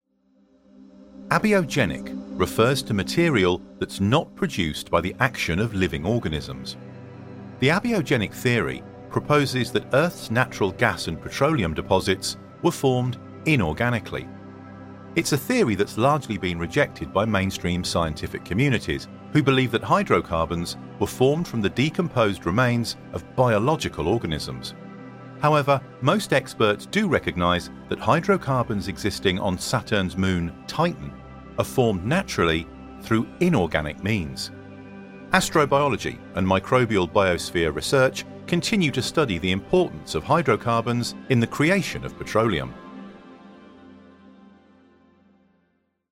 English (British)
Narration
Mic: SHURE SM7B